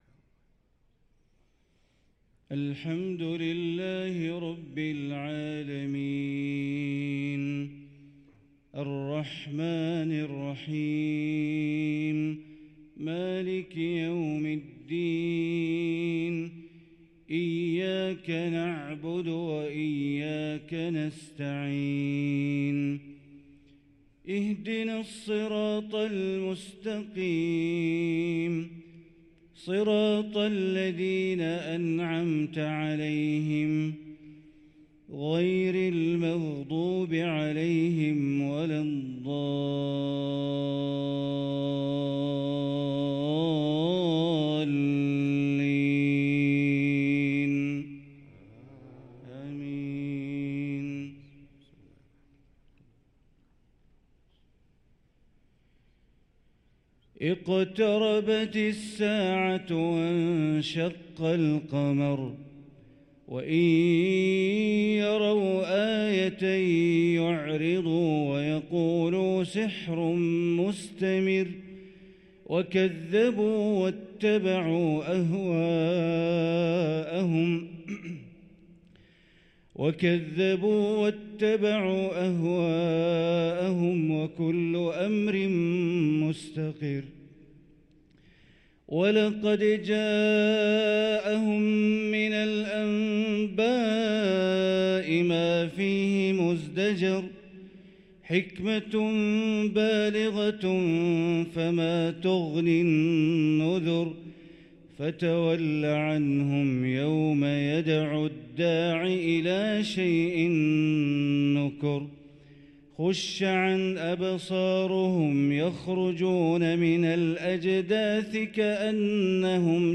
صلاة الفجر للقارئ بندر بليلة 4 ربيع الأول 1445 هـ
تِلَاوَات الْحَرَمَيْن .